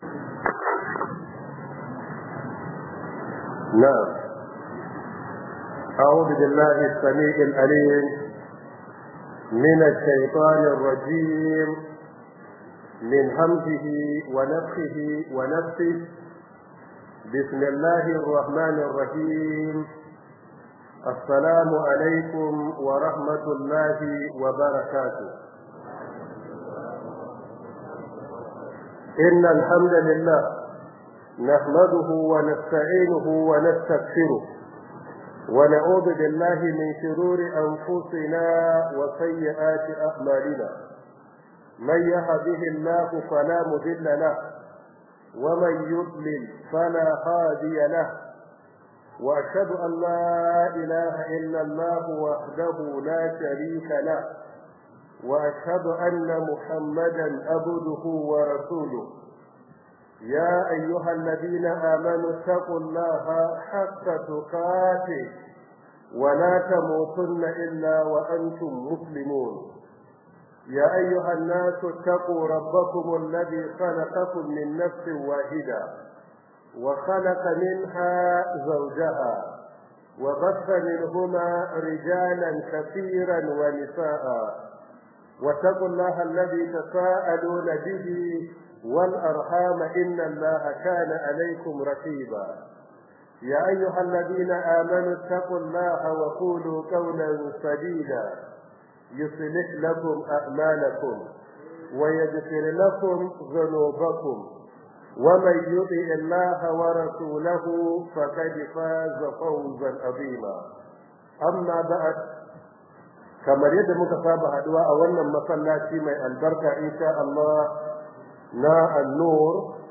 Ramadan Tafseer 014
Ramadan Tafseer by Prof Isah Ali Ibrahim Pantami 1446AH/2025